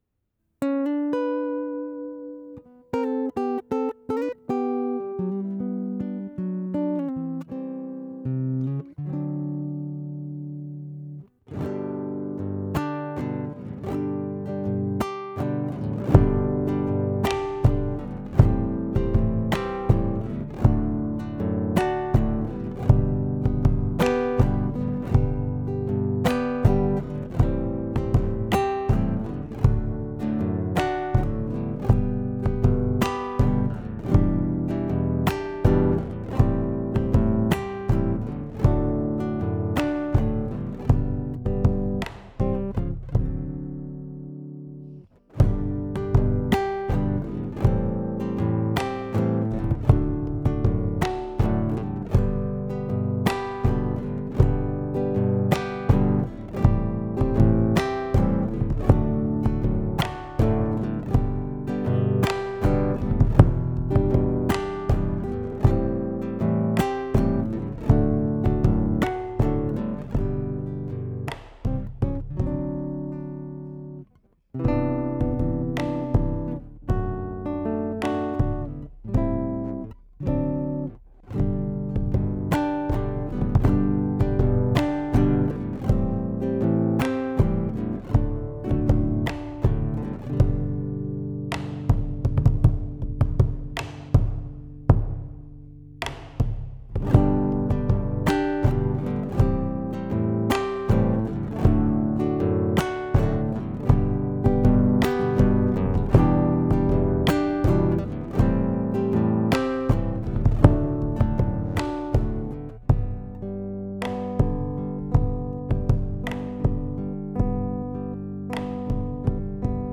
versión instrumental